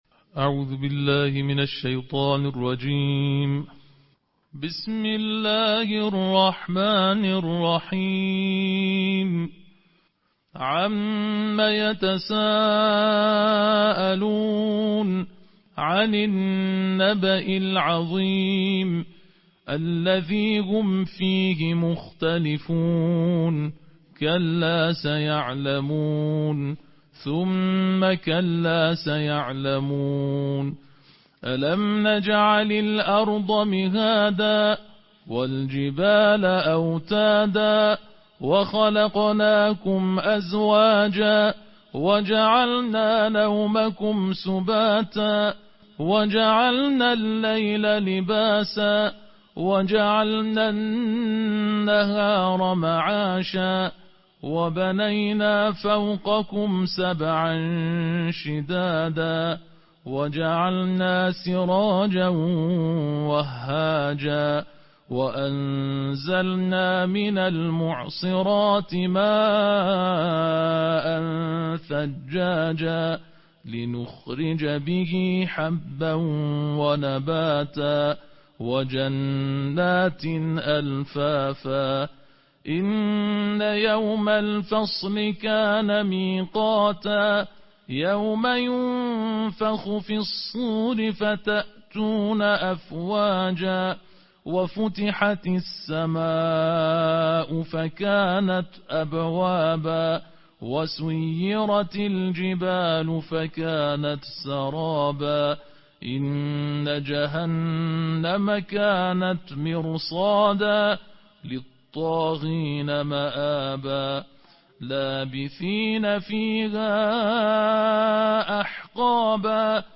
আন্তর্জাতিক ক্বারিদের কন্ঠে ৩০তম পারার তিলাওয়াত + অডিও